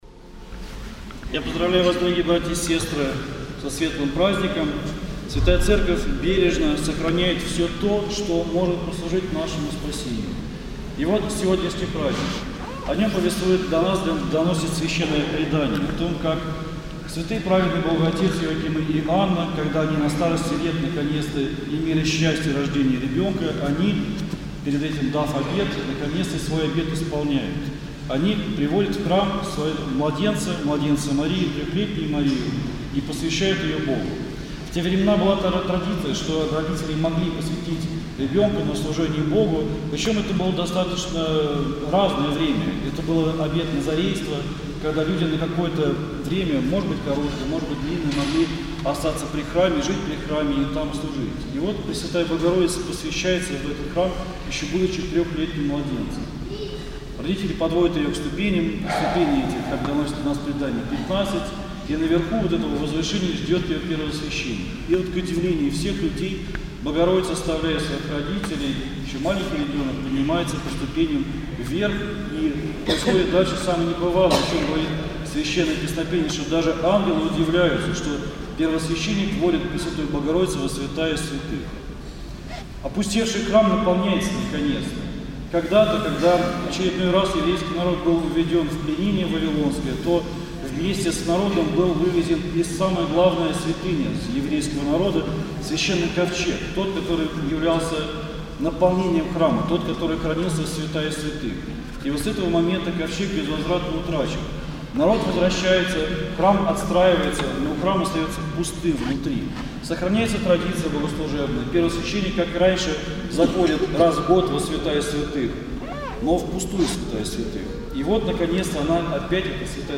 Проповедь на праздник Введения во храм Пресвятой Богородицы